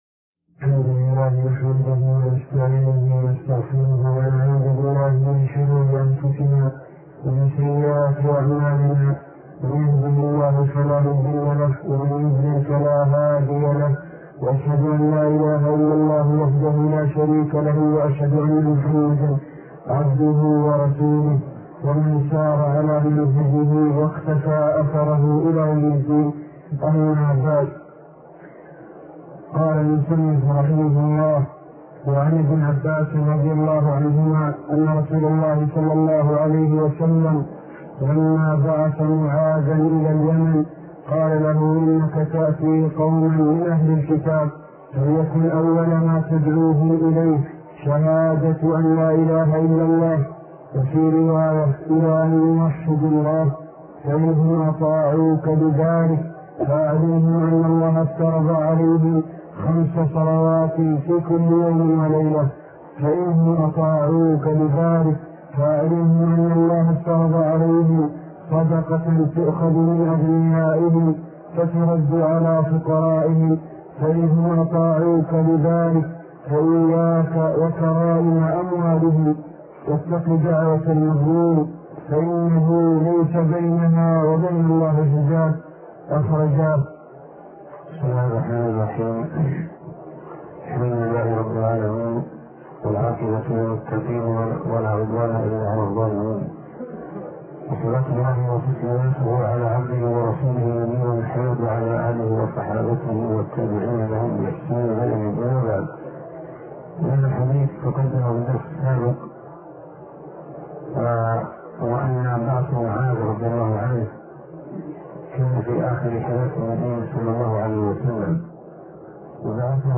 عنوان المادة الدرس ( 22) شرح فتح المجيد شرح كتاب التوحيد تاريخ التحميل الجمعة 16 ديسمبر 2022 مـ حجم المادة 46.13 ميجا بايت عدد الزيارات 205 زيارة عدد مرات الحفظ 122 مرة إستماع المادة حفظ المادة اضف تعليقك أرسل لصديق